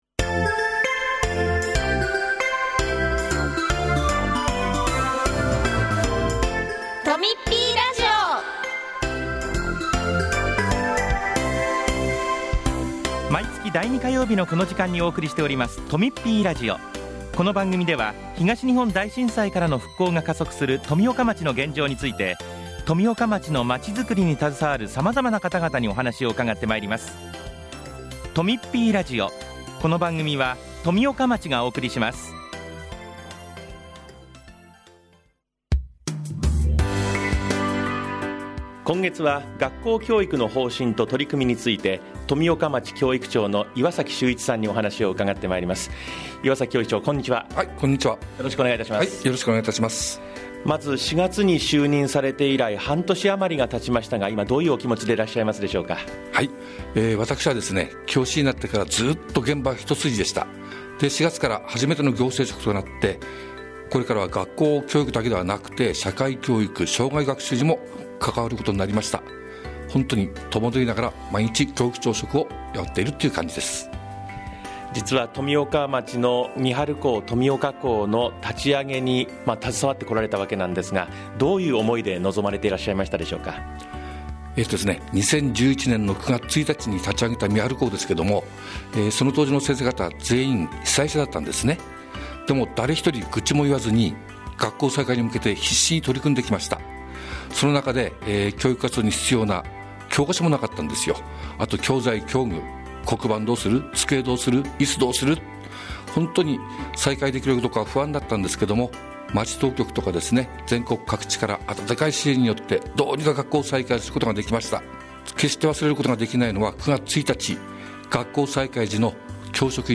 今回は、岩崎秀一教育長が学校教育の方針と取り組みについてお話しします。